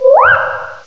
cry_not_minccino.aif